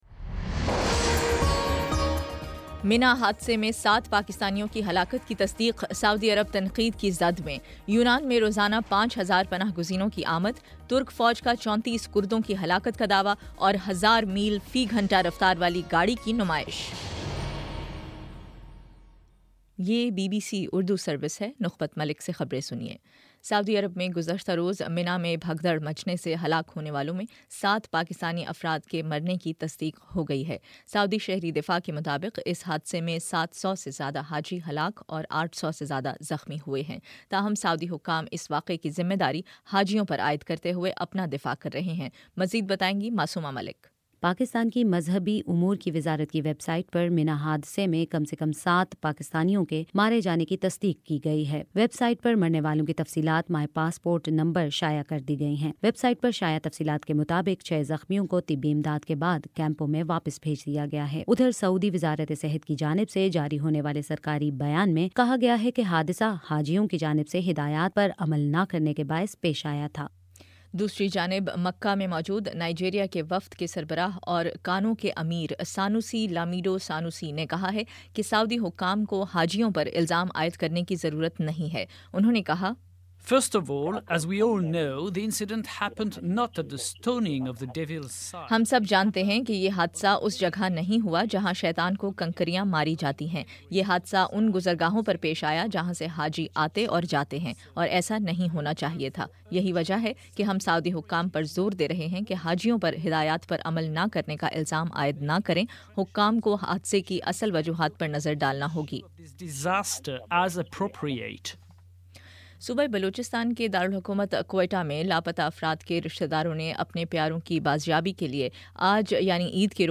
ستمبر25 : شام پانچ بجے کا نیوز بُلیٹن